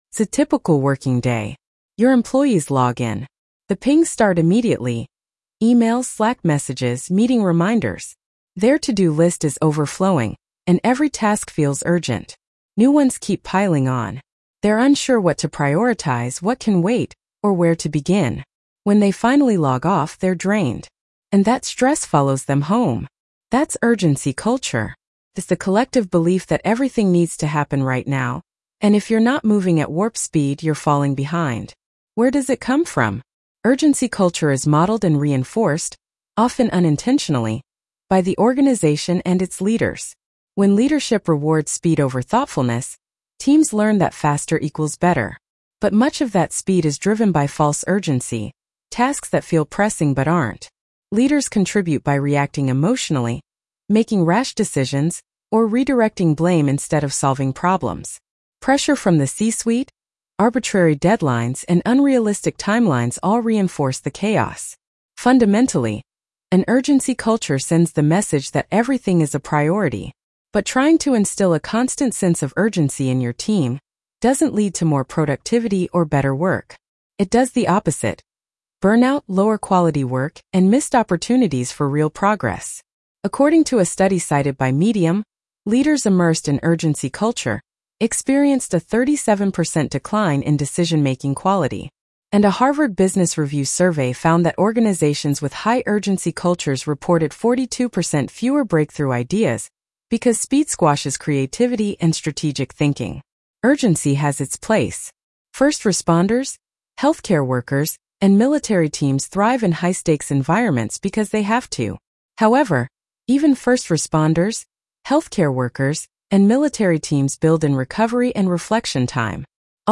Not Everything is an Emergency Blog Narration.mp3